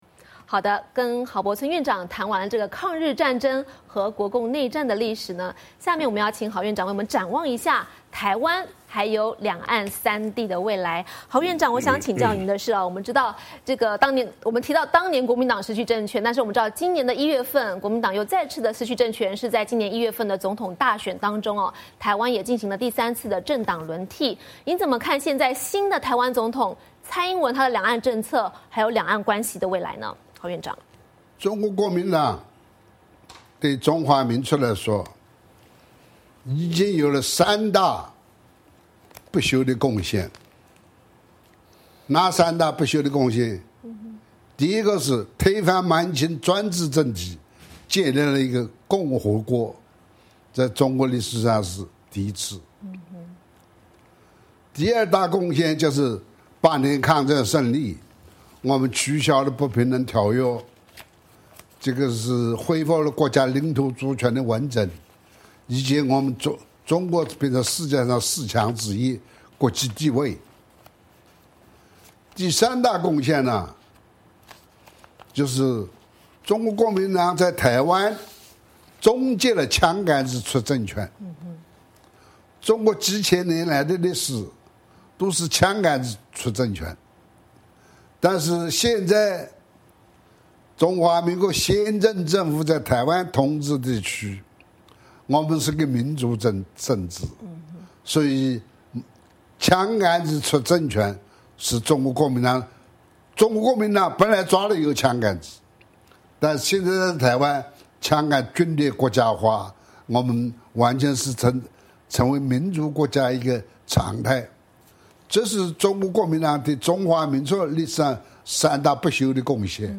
海峡论谈:专访郝柏村 展望两岸未来